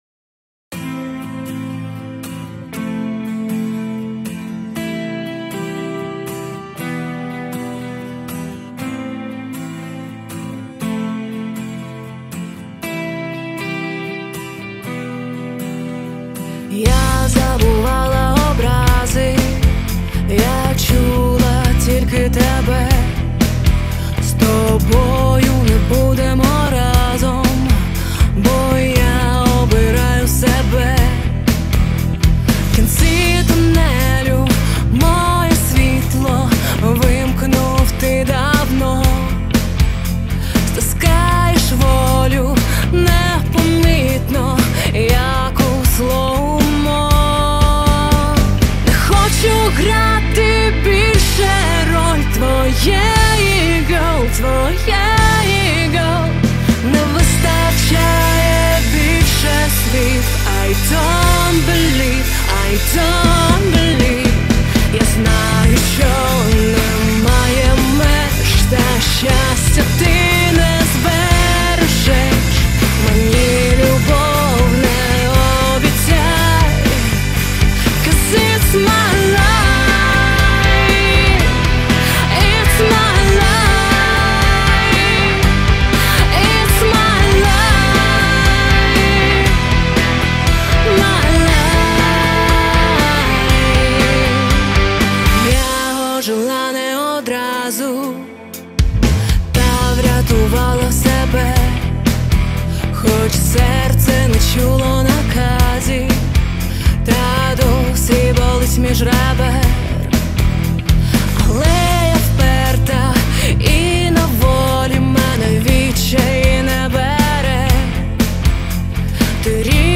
• Жанр: Pop, Rock